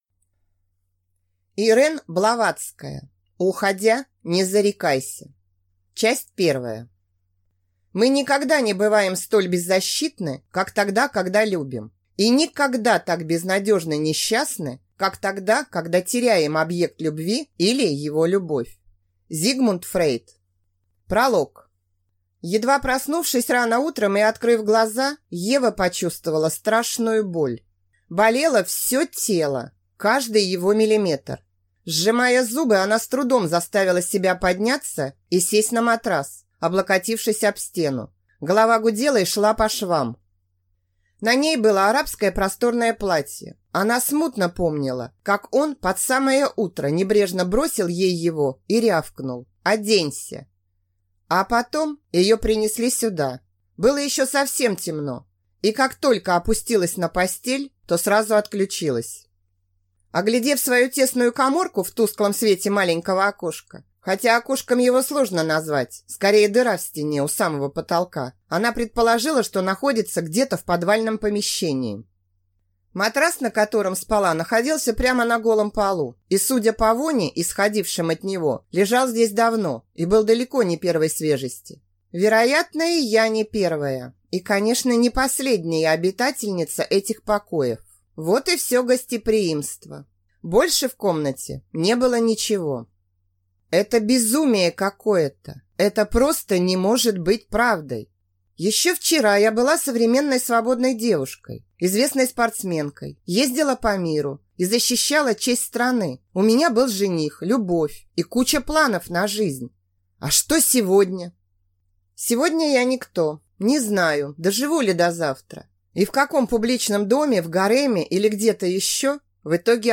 Аудиокнига Уходя не зарекайся | Библиотека аудиокниг